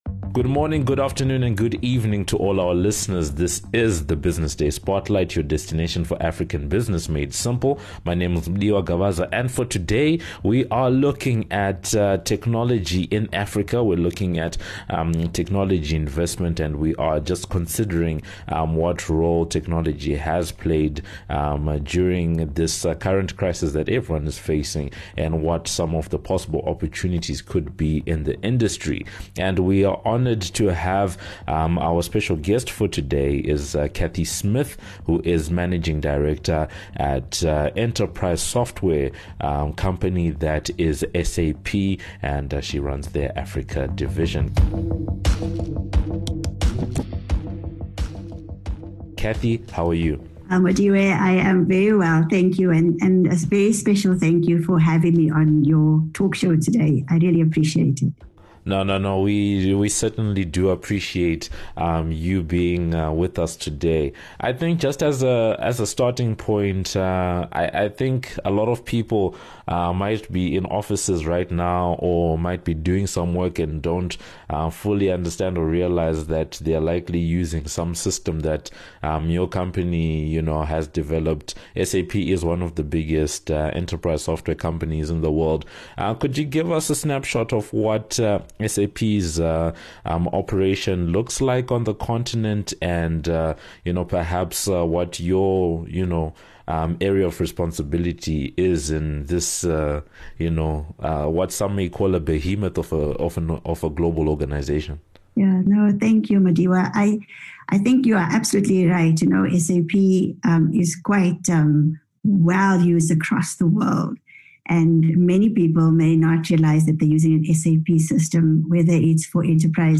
In this edition of the Business Day Spotlight, we’re in discussion with one of the country’s foremost business leaders about technology investment in Africa.